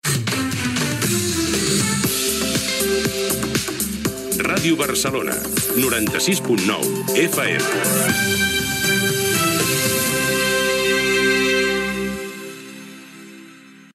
Indicatiu de l'emissora i freqüència